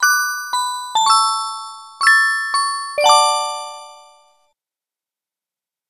シンプルなメッセージサウンドです。
まるでオルゴールの音色が流れるかのような幸せなメロディが鳴り響きます。
最初に耳に届くのは、オルゴールの音色が奏でる柔らかなメロディーです。